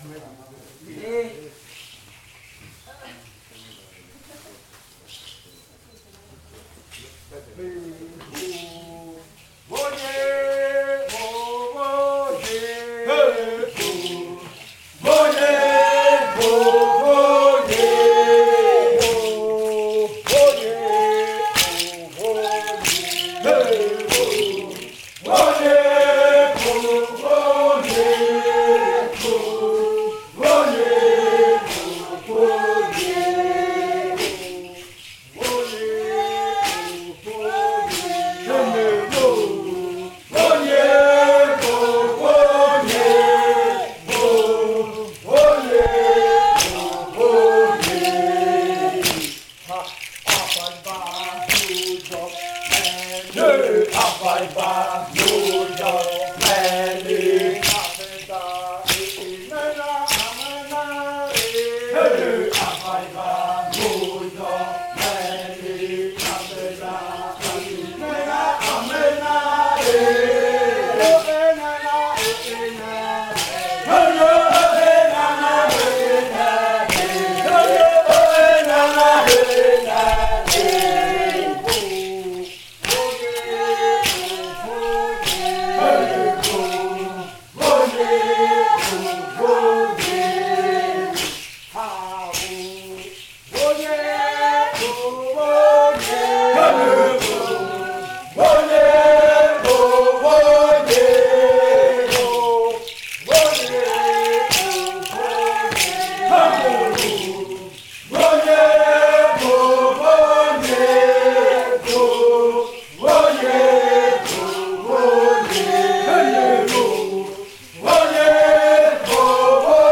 Canto después de medianoche de la variante muruikɨ
con el grupo de cantores bailando en Nokaido. Este canto hace parte de la colección de cantos del ritual yuakɨ murui-muina (ritual de frutas) del pueblo murui, colección que fue hecha por el Grupo de Danza Kaɨ Komuiya Uai con el apoyo de un proyecto de extensión solidaria de la UNAL, sede Amazonia.
with the group of singers dancing in Nokaido.